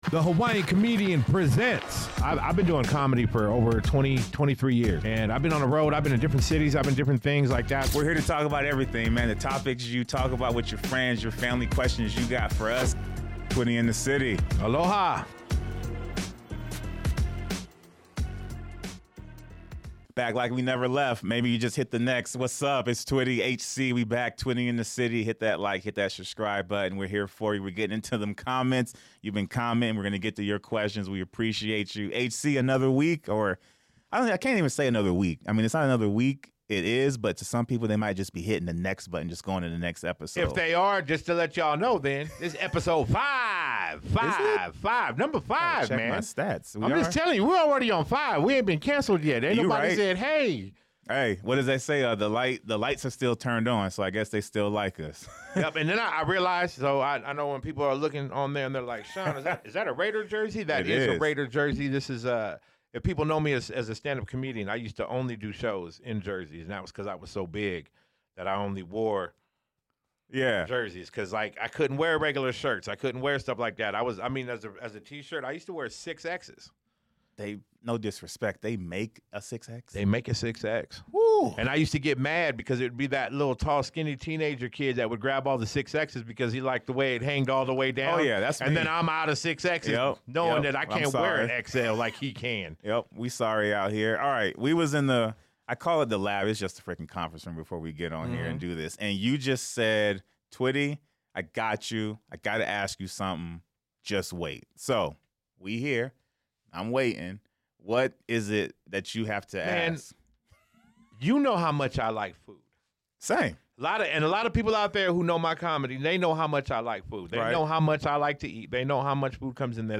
They share personal anecdotes, face tough decisions, and engage in lively banter while making their hilarious picks.